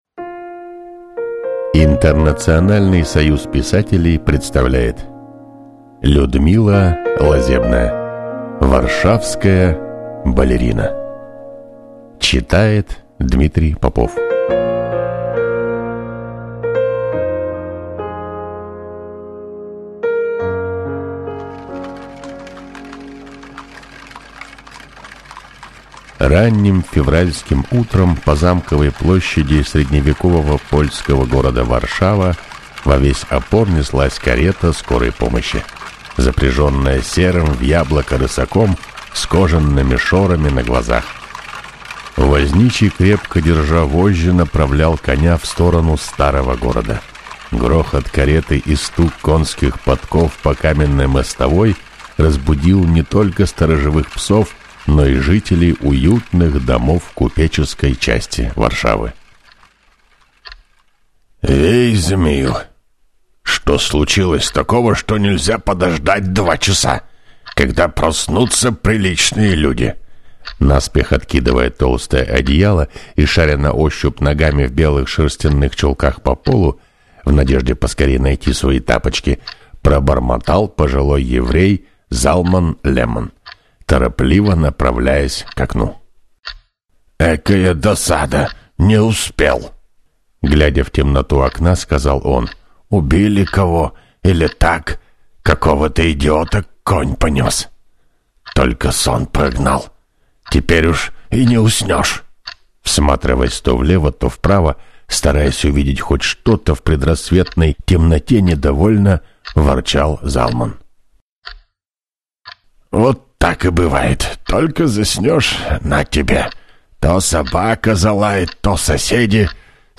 Аудиокнига Варшавская балерина | Библиотека аудиокниг